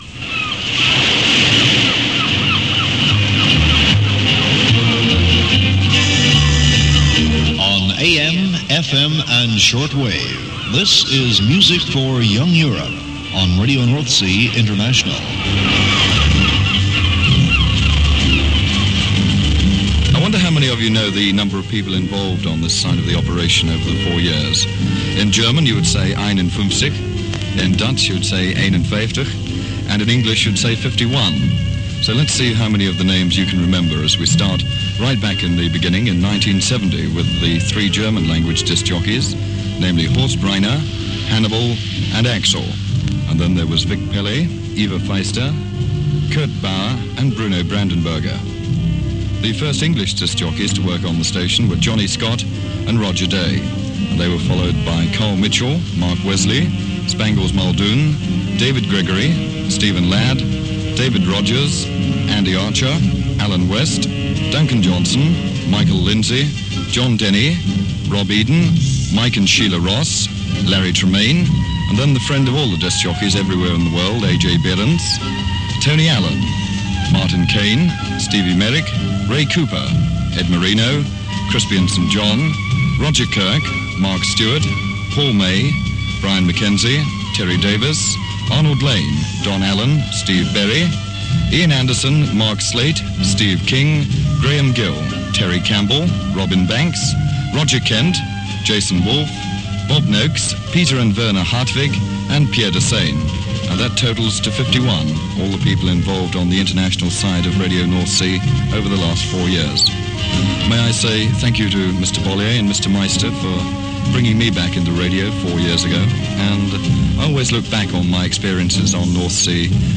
Most of this clip is taken from an excellent quality recording